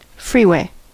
Ääntäminen
IPA: /snɛl.ʋɛɣ/